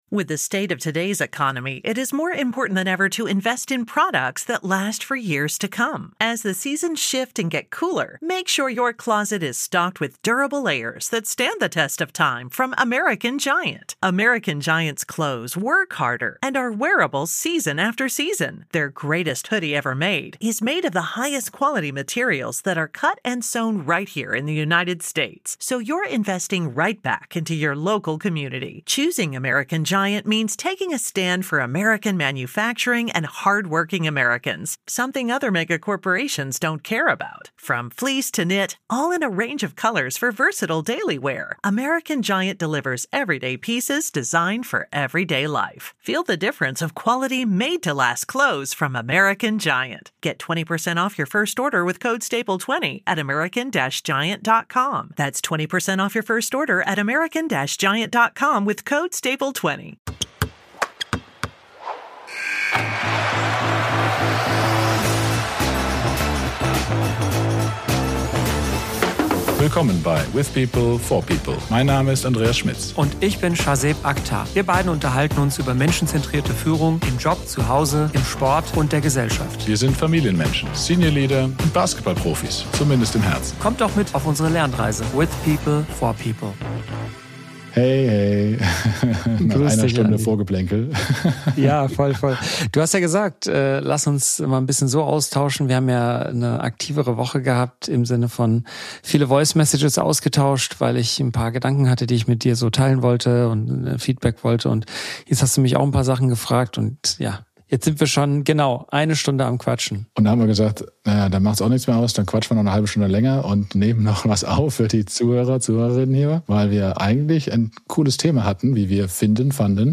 Die beiden Freunde sprechen über dieses spannende Thema intensiv und diskutieren dabei, wie das "echte Leben von Gründern" aussieht: bspw. denkt/träumt man Tag und Nacht von der Arbeit, oder arbeitet man 24/7?